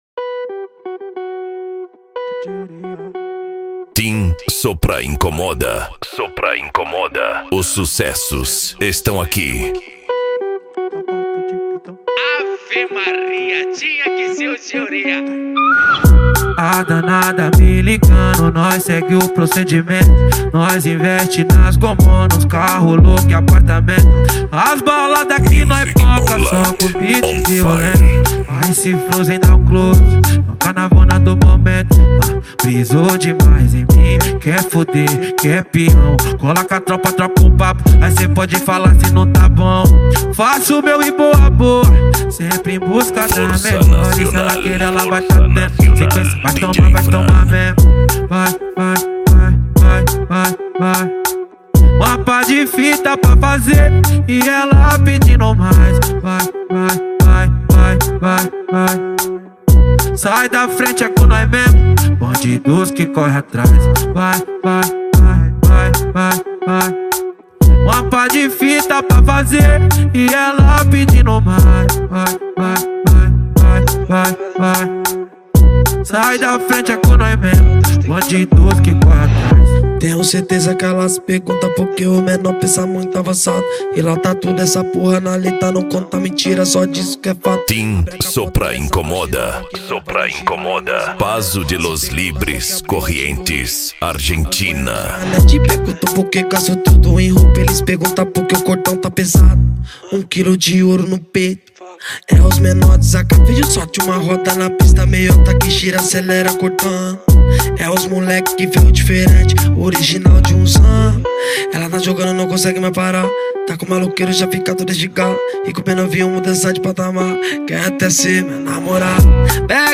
Remix
Funk
Bass